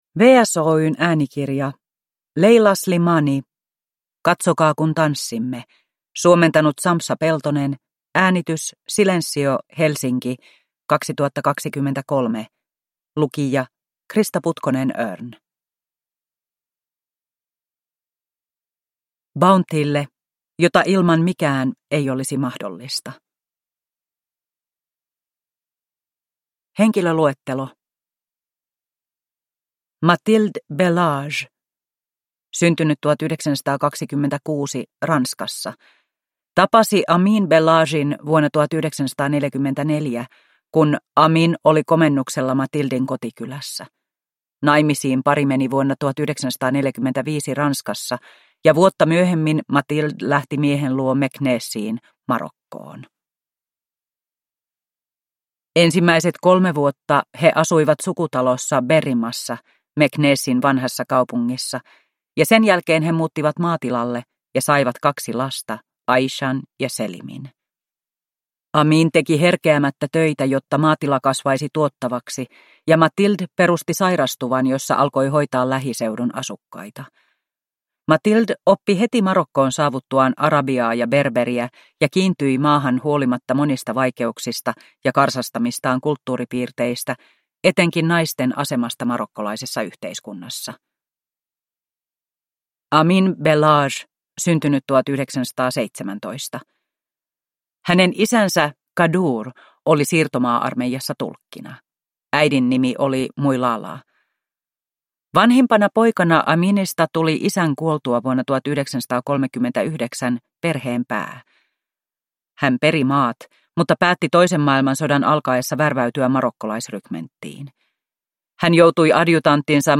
Katsokaa kun tanssimme – Ljudbok – Laddas ner